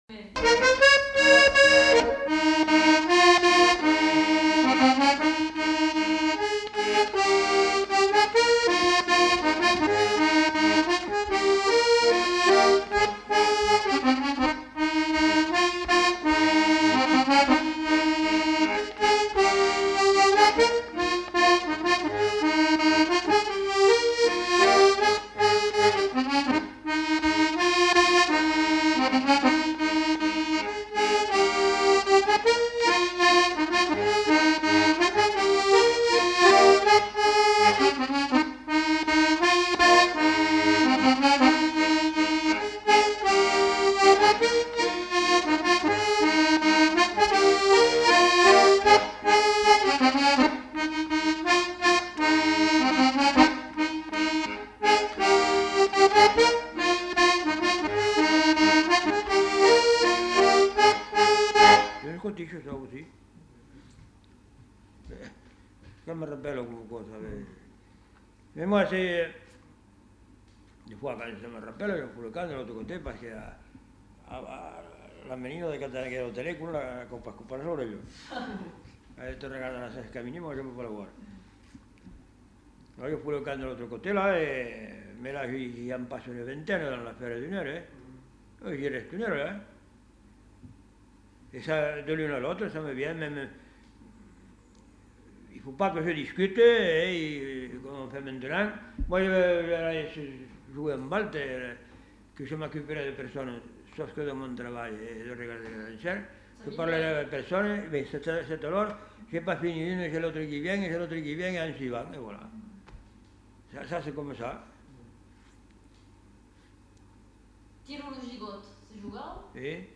Lieu : Beaumont-de-Lomagne
Genre : morceau instrumental
Instrument de musique : accordéon diatonique
Danse : scottish